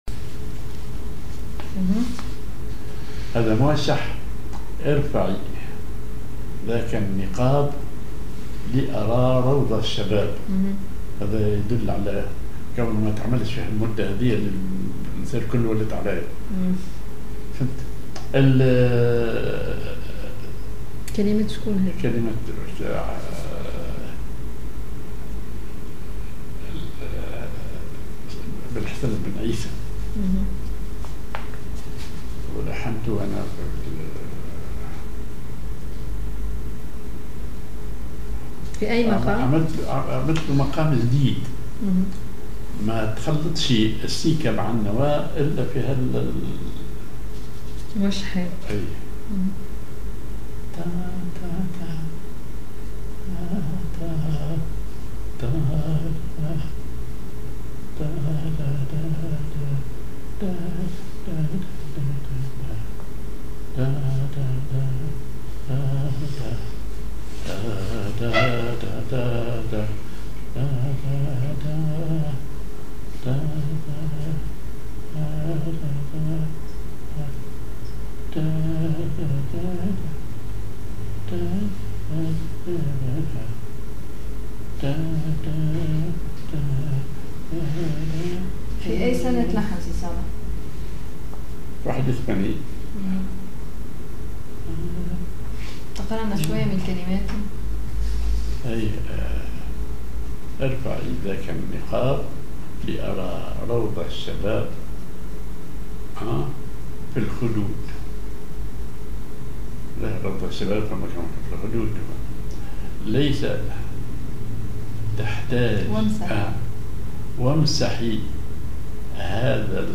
ar النوا سيكاه
ar سماعي ثقيل
موشح